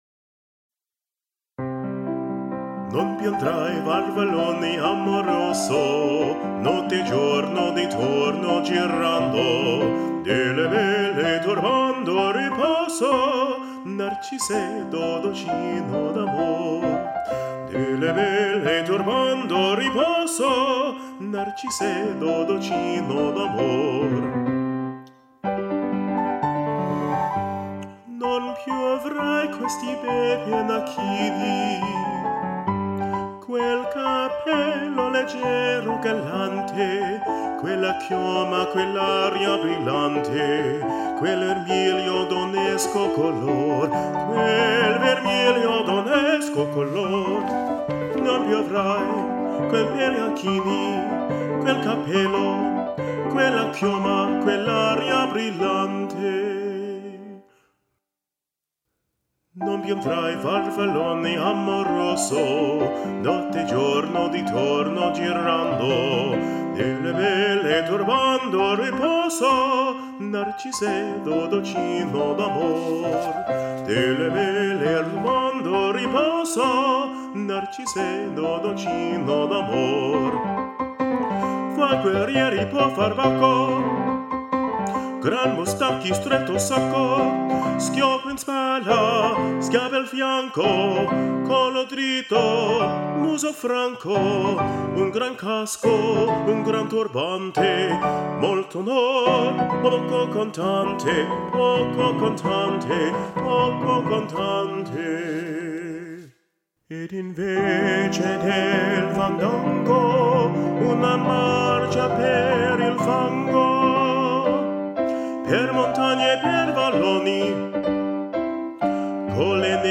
Under the tutelage of the famed opera singer (and my grandfather) Andrew Frierson, I have honed my own Baritone sound, focused on expressiveness and musicality.